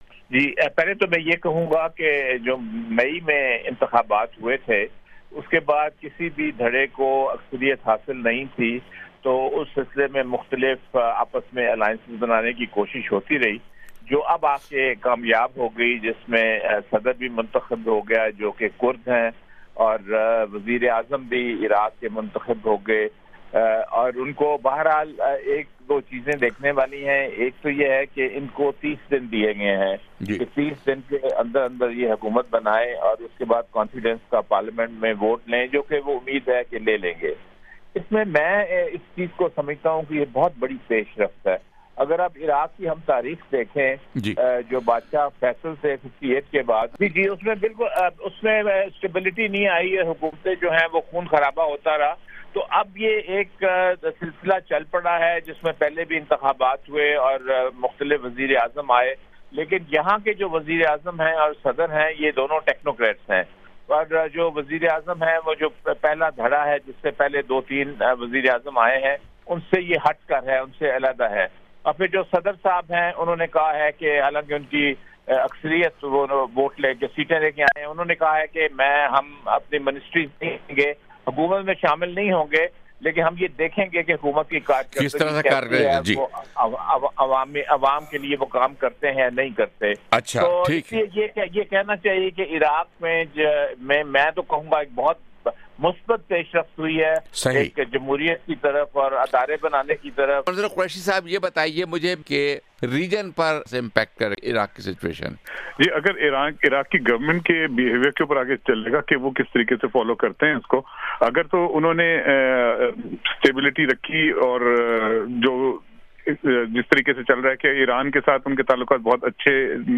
JR discussion- Iraq's new government and the region